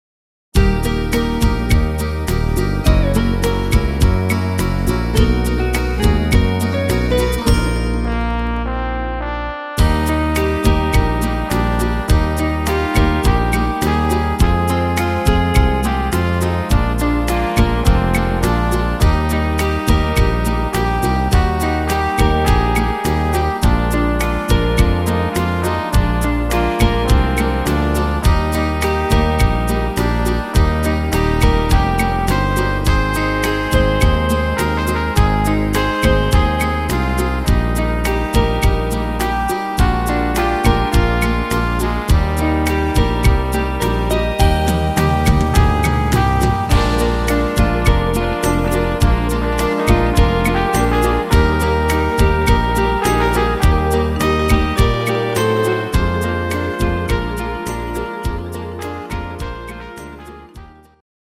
instr.Trompete